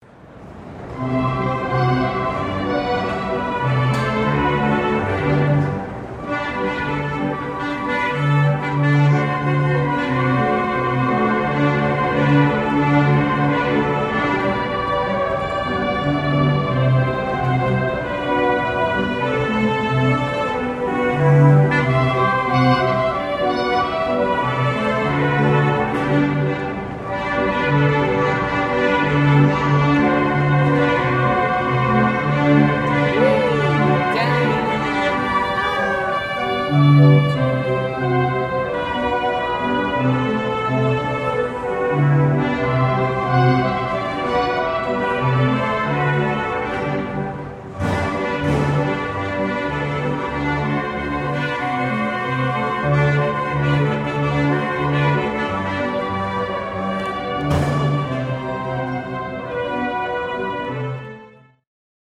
Звук карусели (по кругу катаются разные персонажи лошади, лодки, машины и т.д.) (01:02)